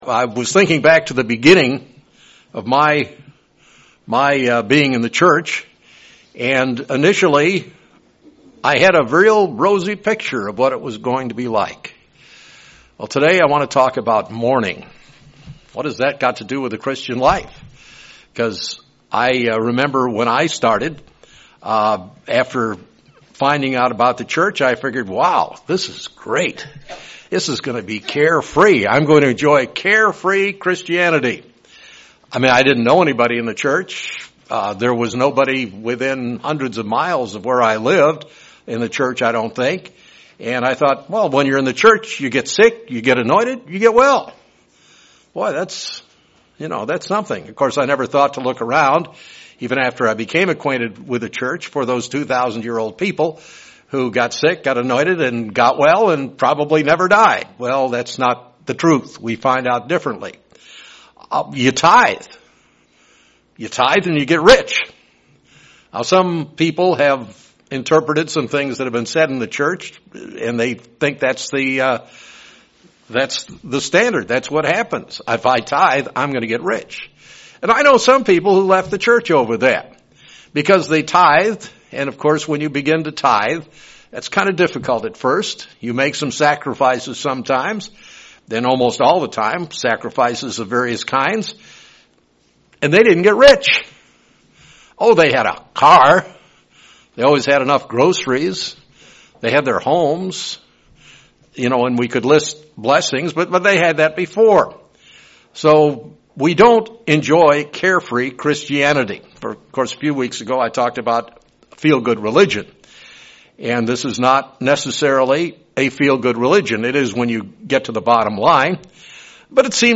In this sermon, the speaker looks into the topic of mourning and addresses; why we mourn and where we find comfort.
Given in Springfield, MO
UCG Sermon Studying the bible?